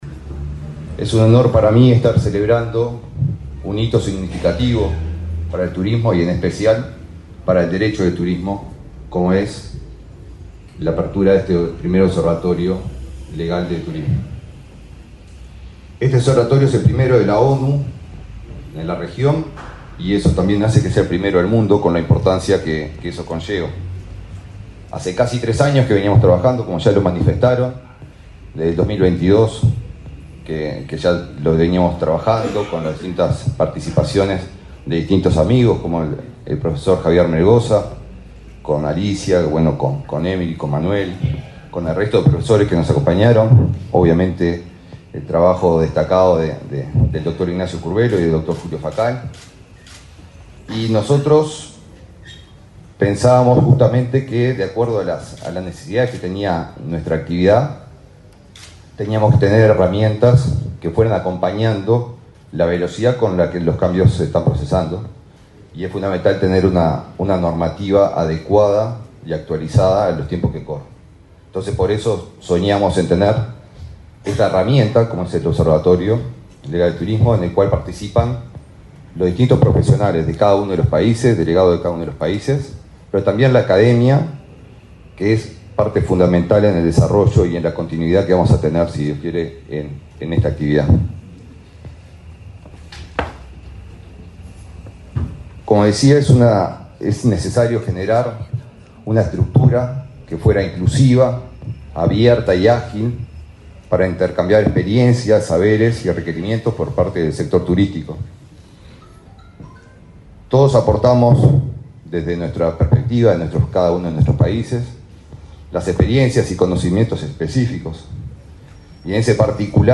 Palabras del ministro de Turismo, Eduardo Sanguinetti
El ministro de Turismo, Eduardo Sanguinetti, participó, este martes 3 en Montevideo, en la inauguración del Observatorio de Derecho del Turismo de